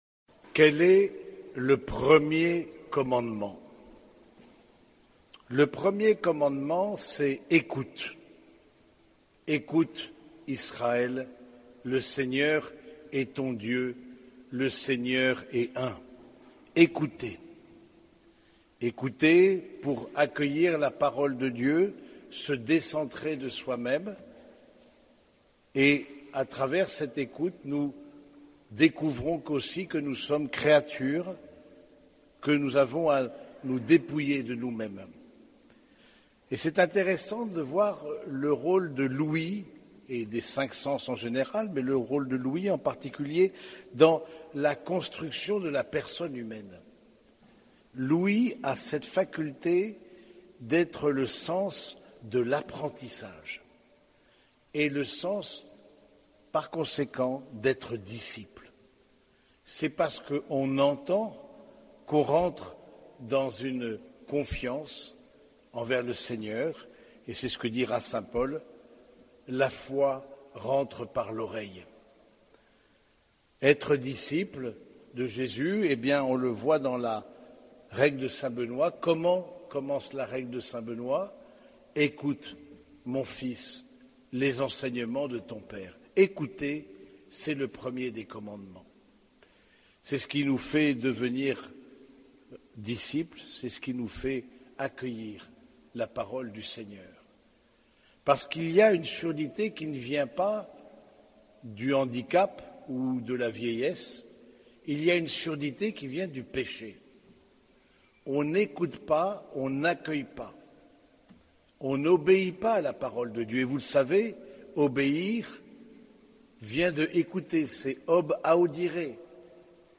Homélie du 23e dimanche du Temps Ordinaire